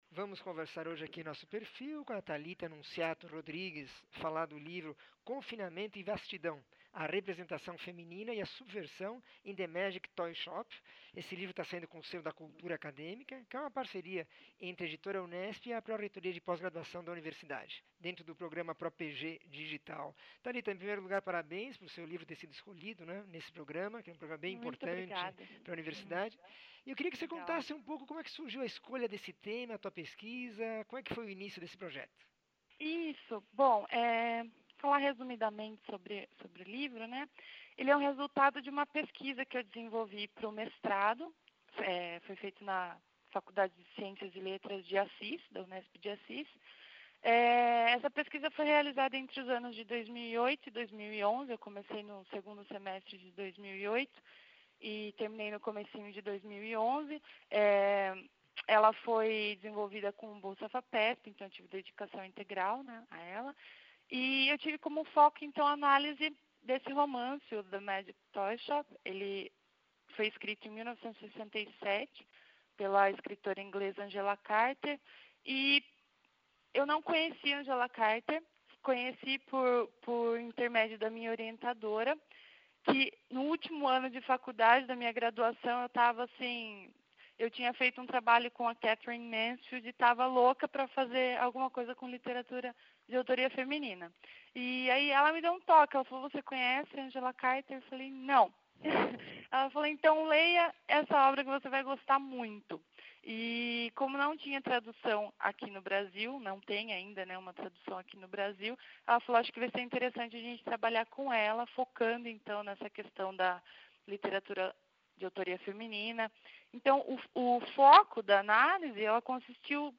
entrevista 1713
Entrevista